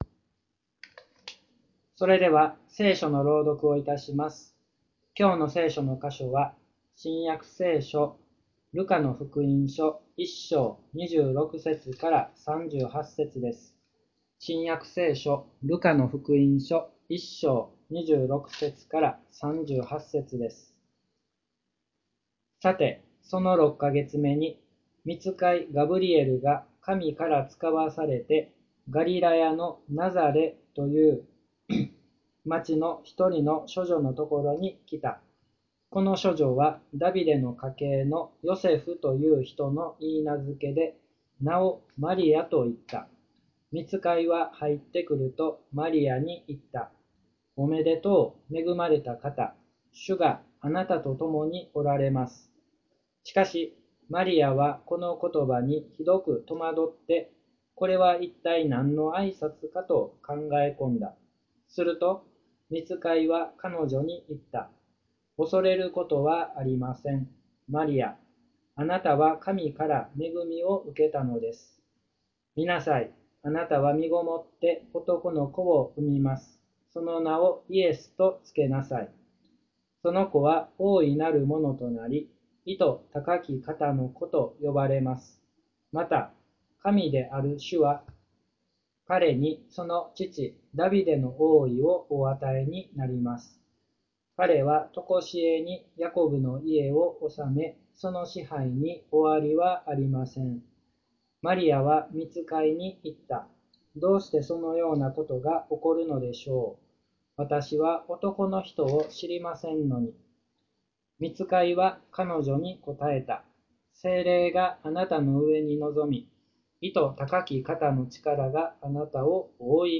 礼拝説教から ２０２０年１２月１３日